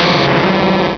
Cri de Volcaropod dans Pokémon Rubis et Saphir.